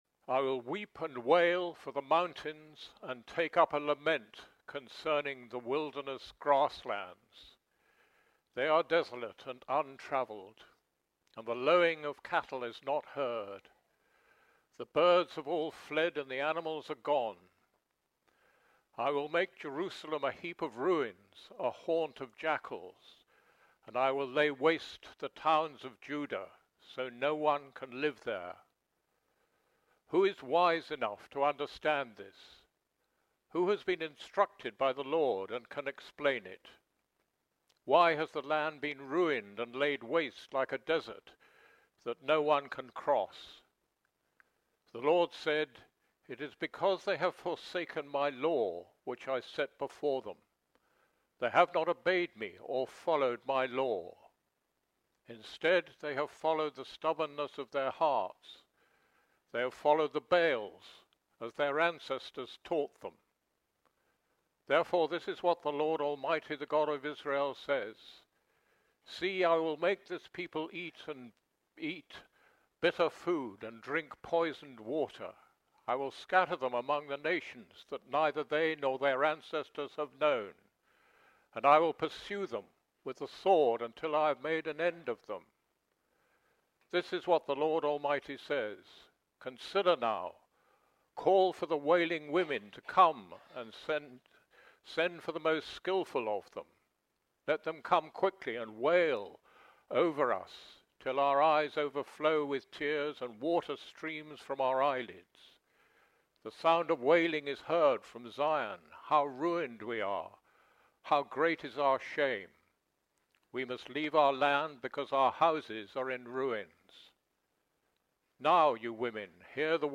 Here is the link to the bible reading being read at the beginning of the message Jeremiah 9:10-24